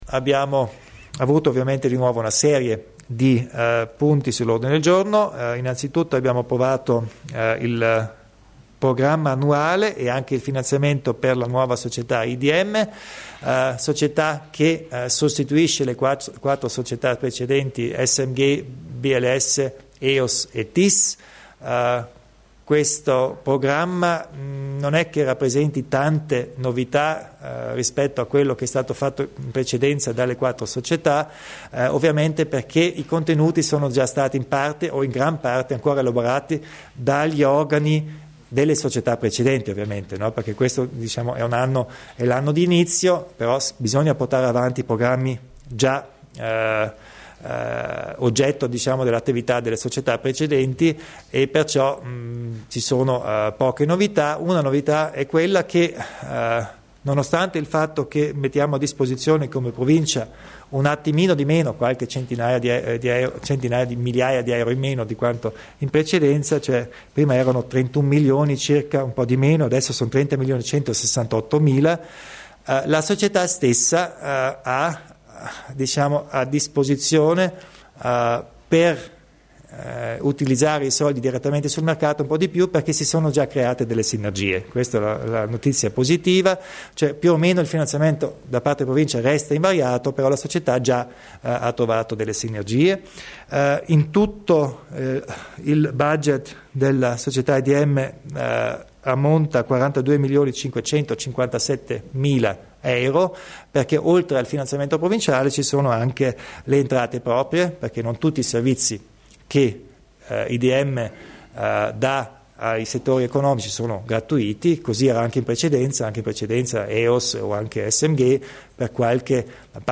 Il Presidente Kompatscher illustra il programma di attività di IDM